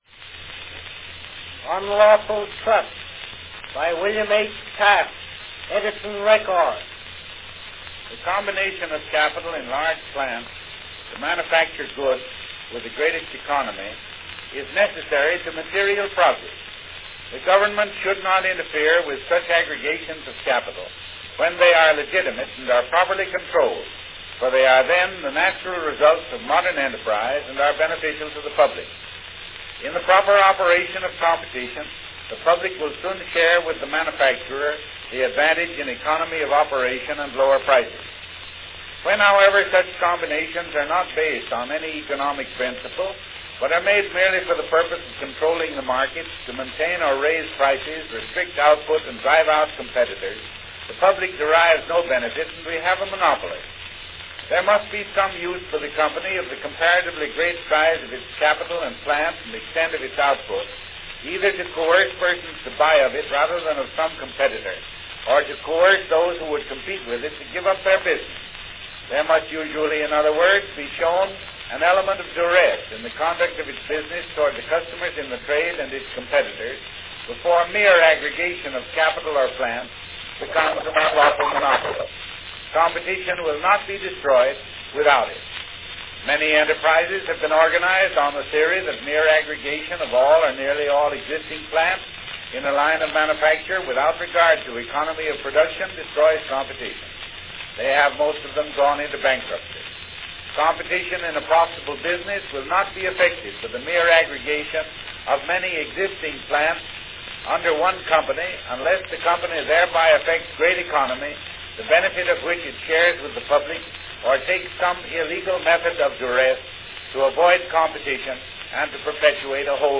Candidate William H. Taft shares his views on monopoly regulation in this 1908 presidential campaign recording.
Category Talking
Announcement "Unlawful Trusts, by William H. Taft.  Edison record."
About two-thirds into the recording you will notice a damaged section, about 2 seconds long, garbling Taft saying, "becomes an unlawful monopoly".   The damage seems to have been caused perhaps by a recording stylus at one time being used (hopefully) accidentally instead of a reproducing stylus.